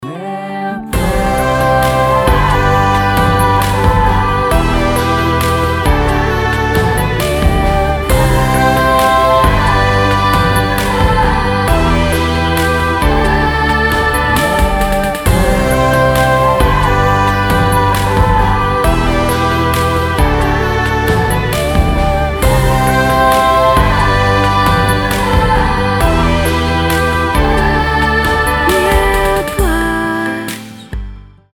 • Качество: 320, Stereo
красивые
dance
спокойные